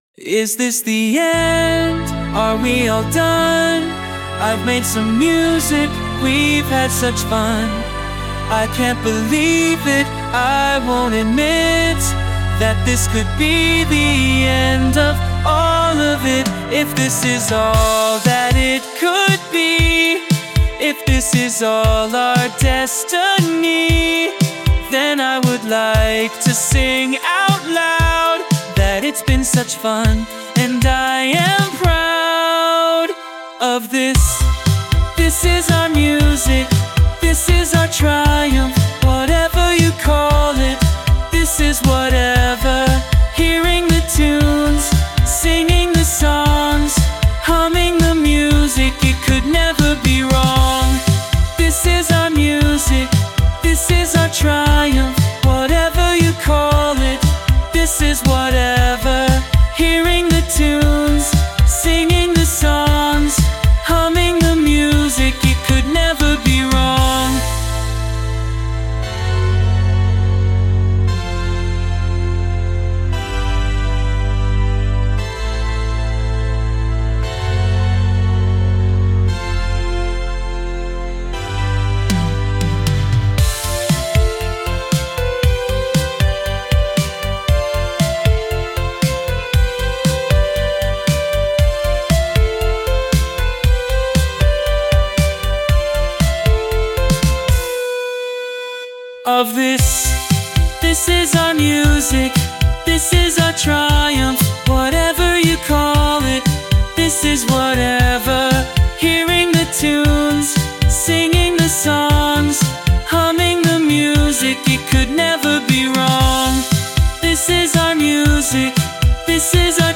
Vocals by Suno
Backing vocals by Emvoice App (Lucy and Jay)
I wrote the instrumental of the tune using Ableton, and then wrote some lyrics for it.
I extracted the vocals using Suno's own Stem Separator, and popped those back into Ableton, and then added extra layers of lyrics using Emvoice.
Recorded_Zee_(inst)_(Cover)_mp3.mp3